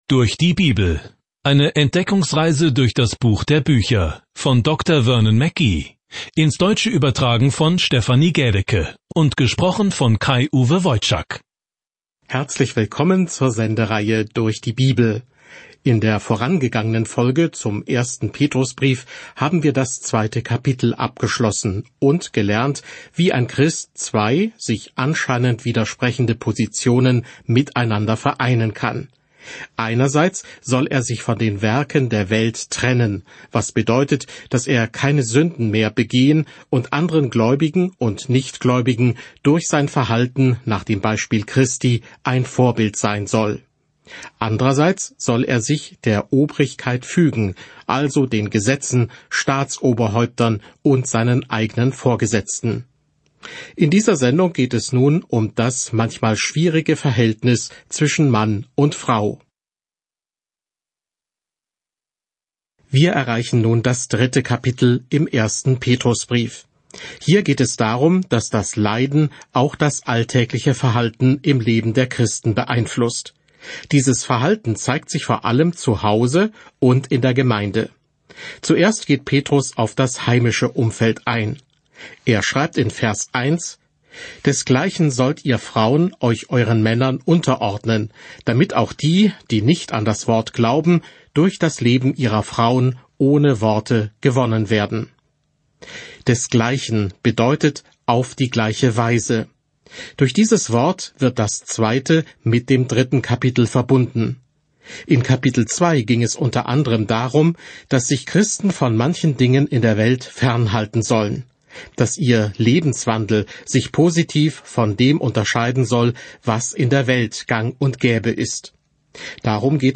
Petrus, während Sie sich die Audiostudie anhören und ausgewählte Verse aus Gottes Wort lesen.